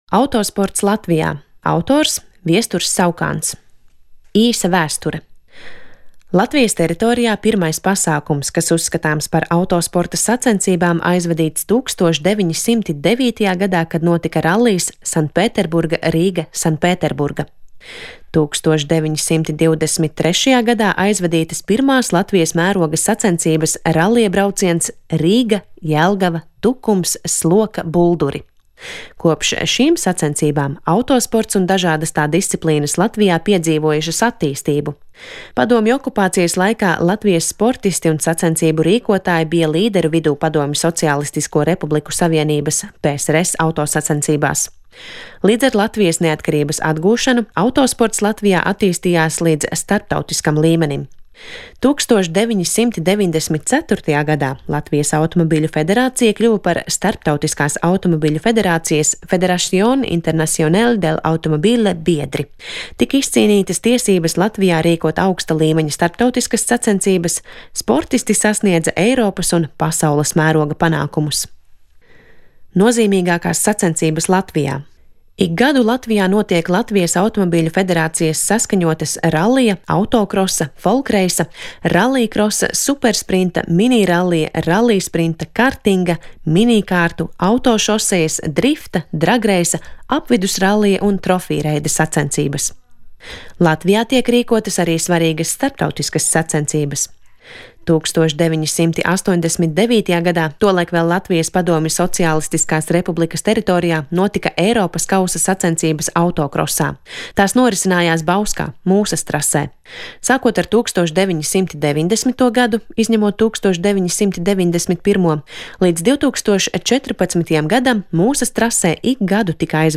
2019. gadā, atzīmējot Nacionālās enciklopēdijas drukātā sējuma "Latvija" (2018) pirmo gadadienu, Latvijas Nacionālās bibliotēkas un Latvijas Radio darbinieki sagatavoja Nacionālās enciklopēdijas drukātā sējuma šķirkļu audio ierakstus.